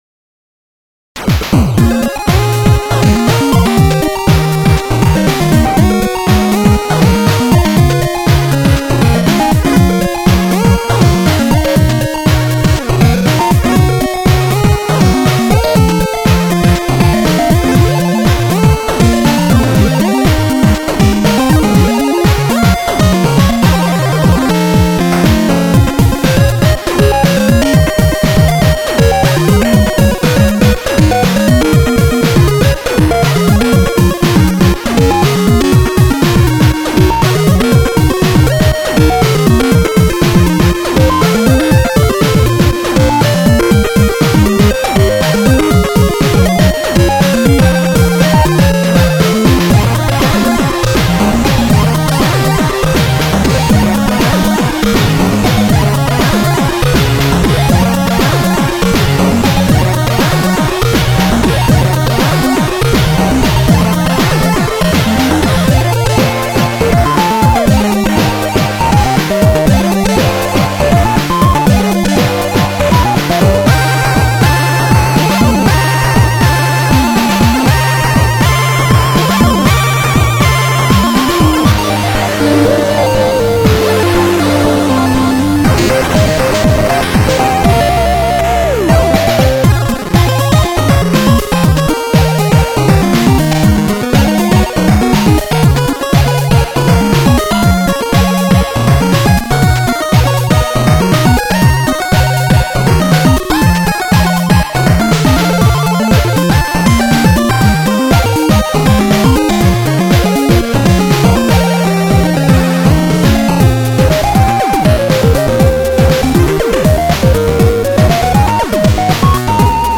※ (　　)内は、使用音源チップです。
(2A03)